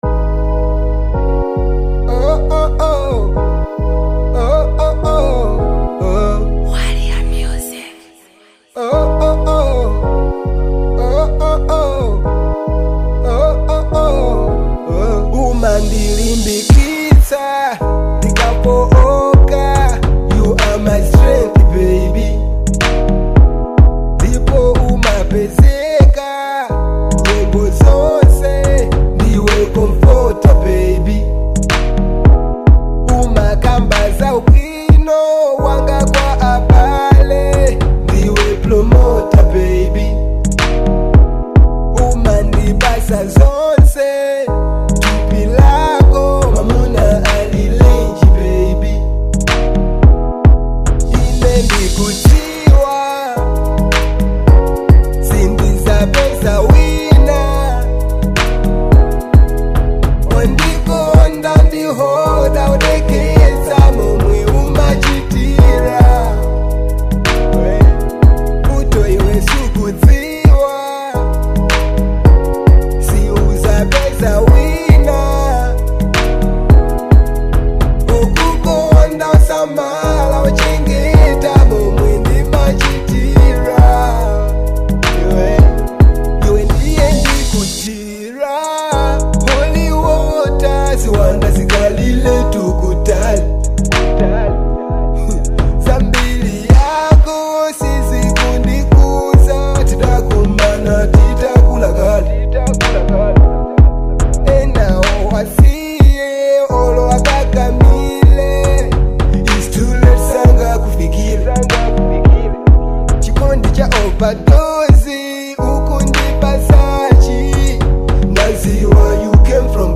Genre : Afro Dancehall